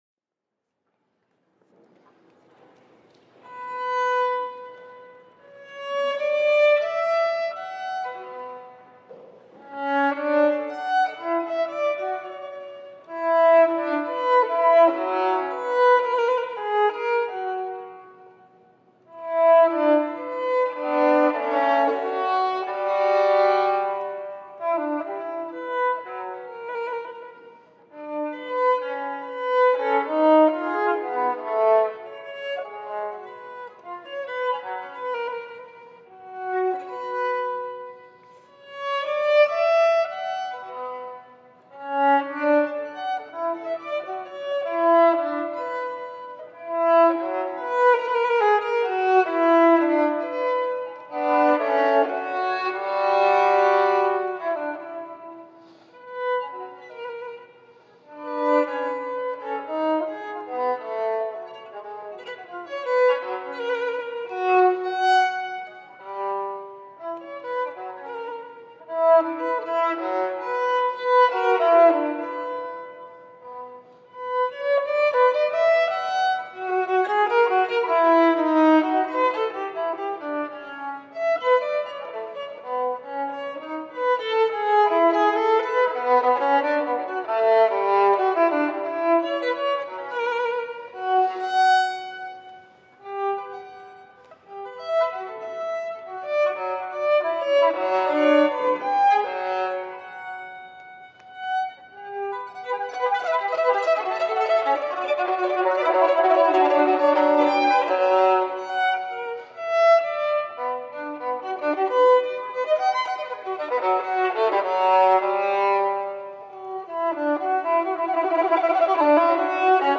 St Vedast Foster Lane
Harpsichord